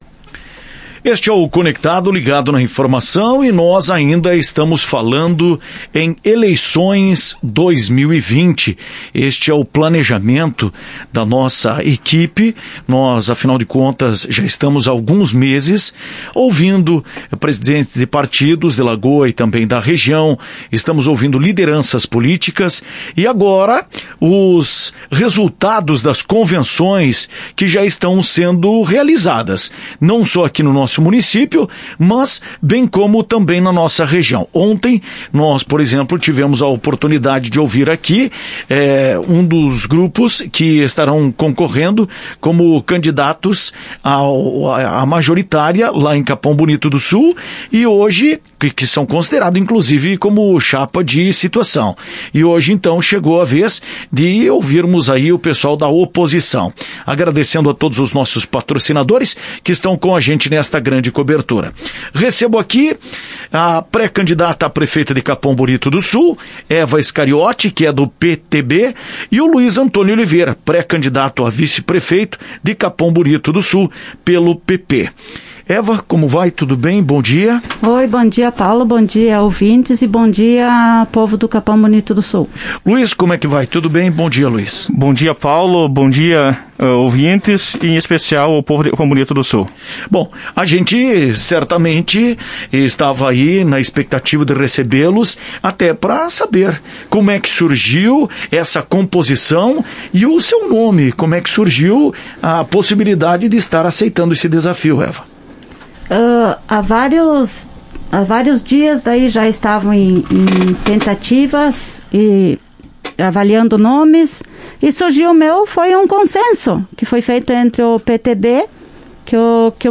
Ouça a entrevista e saiba mais a preparação da dupla para as eleições municiais de Capão Bonito do Sul.